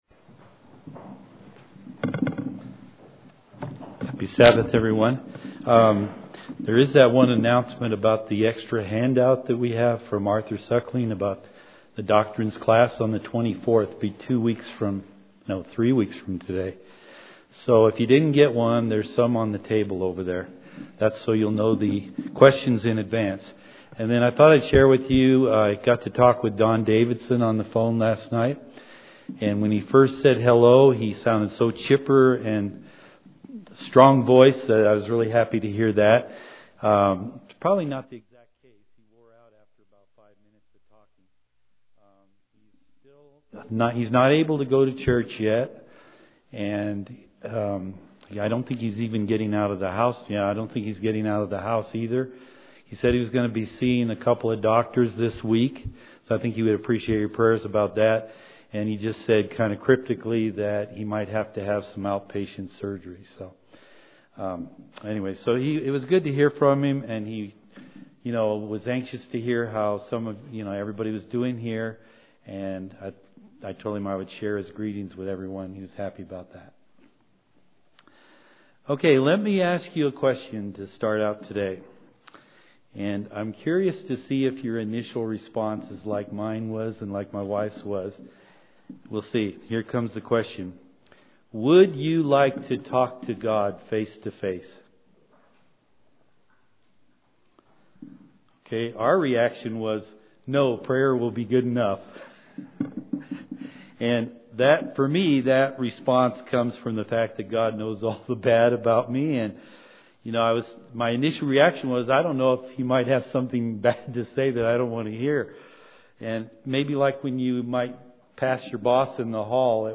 Sermons
Given in Colorado Springs, CO